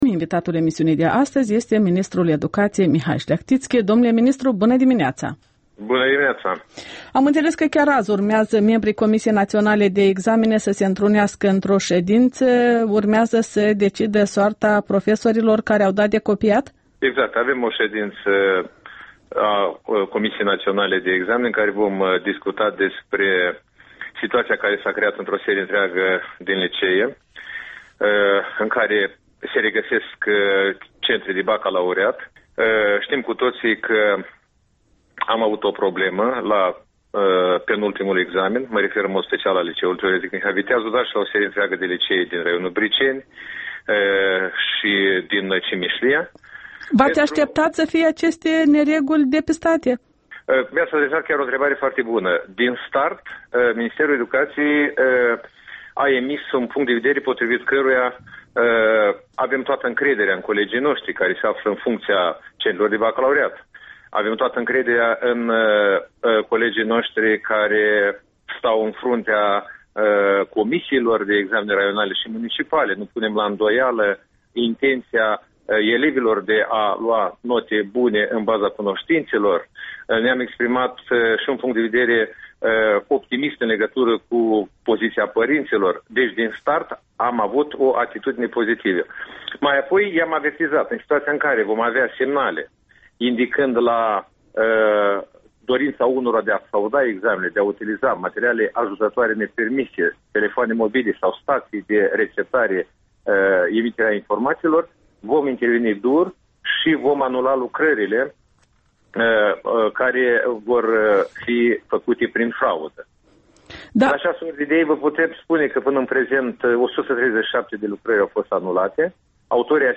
Interviul dimineții la EL: despre baccalaureat și mită cu ministrul Mihal Șleahtițchi